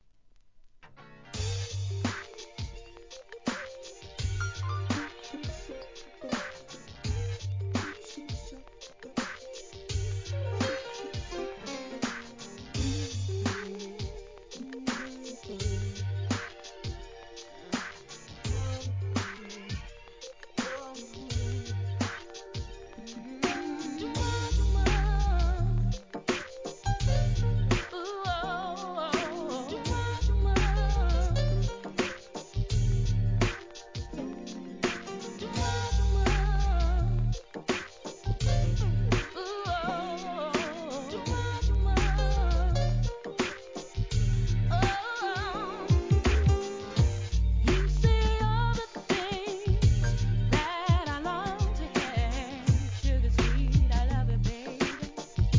HIP HOP/R&B
JAZZYなUK SOUL